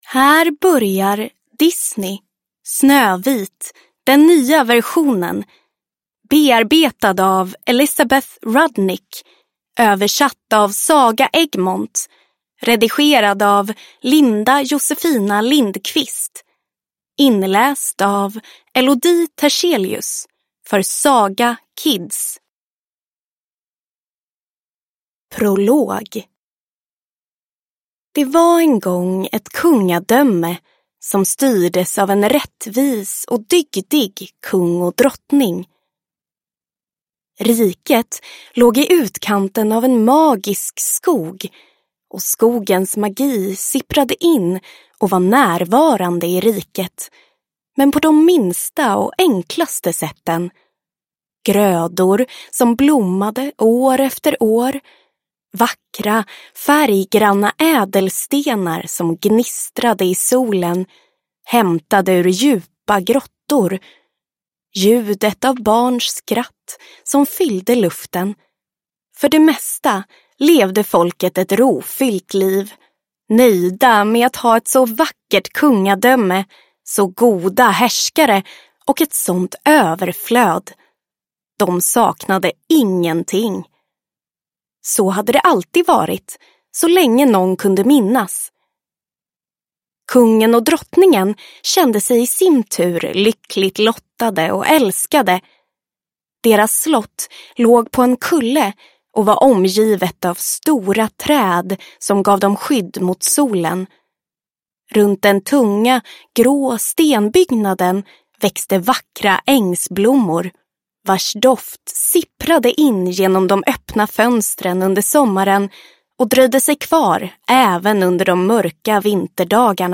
Snövit – Filmversionen – Ljudbok